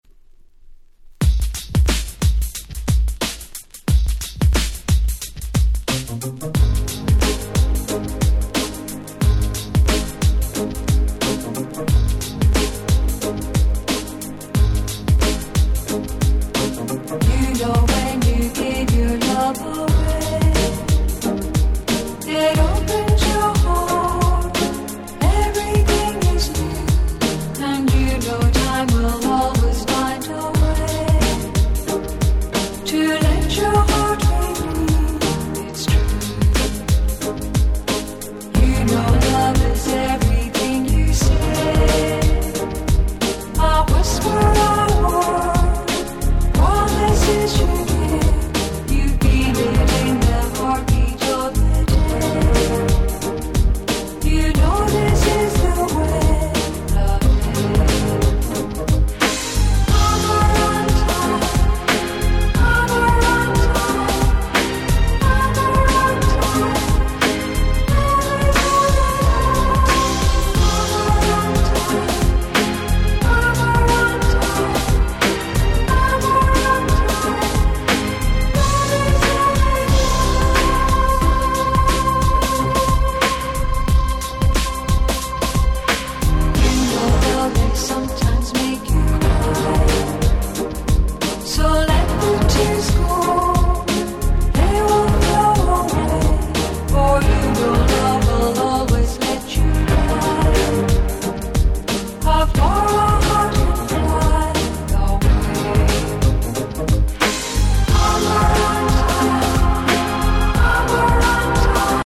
Ground Beatのリズムを下に敷くだけであら不思議、壮大感3割り増しなのです！！